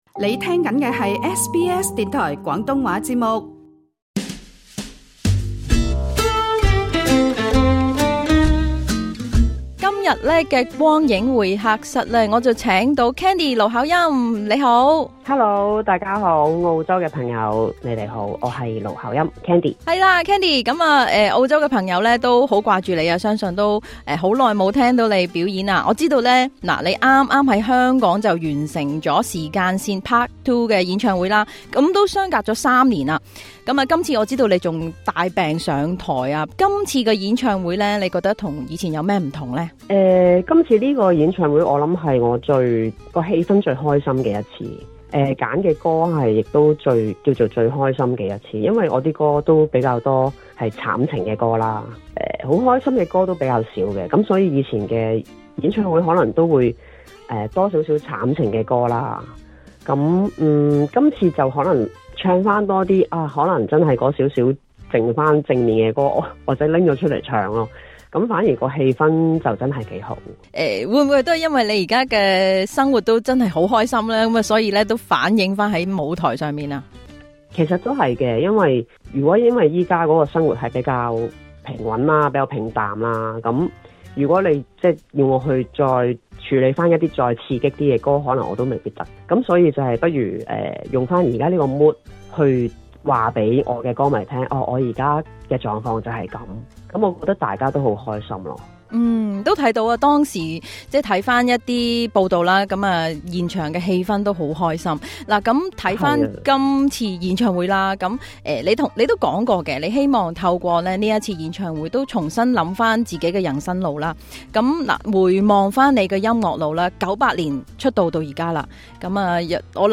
今集《光影會客室》專訪盧巧音，由情緒低谷到跑步重生，半百的她，活出真正奢侈的人生。